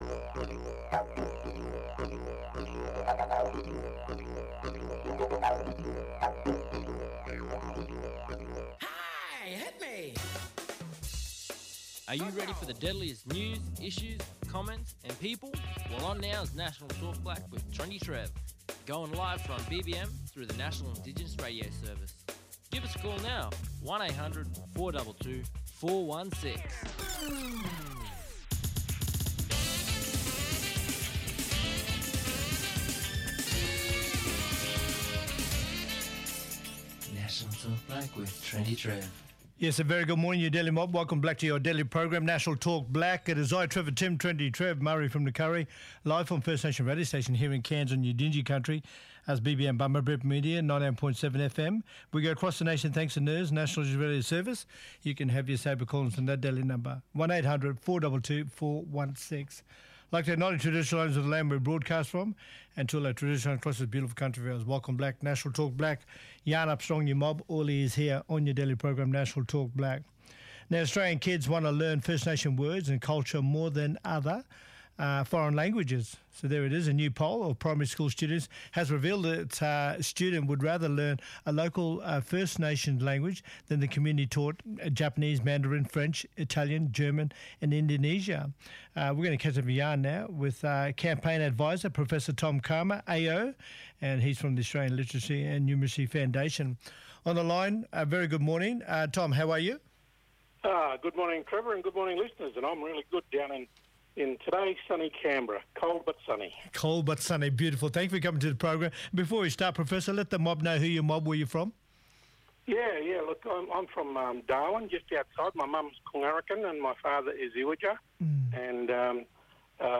Professor Tom Calma, Chancellor of the University of Canberra, talking about Australian kids want to learn First Nations words more than other, foreign languages. A new poll of primary school students has revealed that children would rather learn a local First Nations language than the commonly-taught Japanese, Mandarin, French, Italian, German and Indonesian.